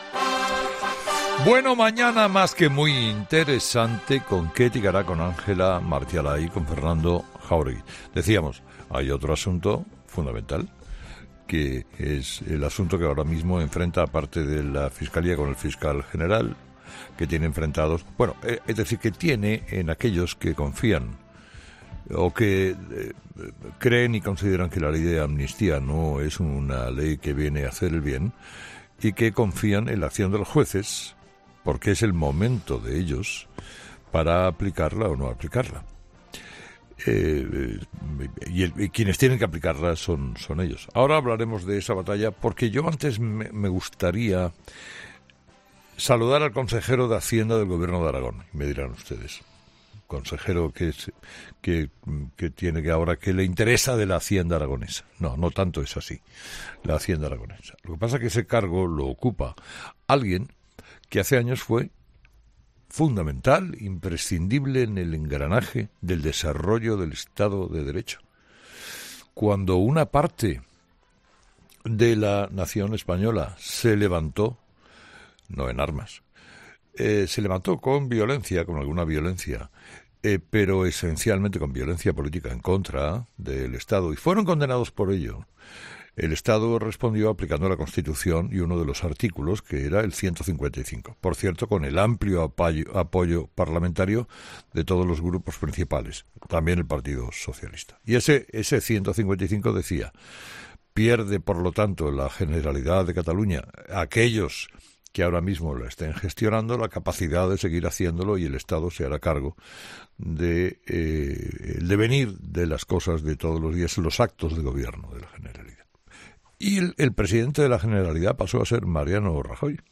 Roberto Bermúdez de Castro, conocido por muchos como 'Míster 155', fue el encargado de aplicar el artículo en la Generalitat y cuenta a Carlos Herrera...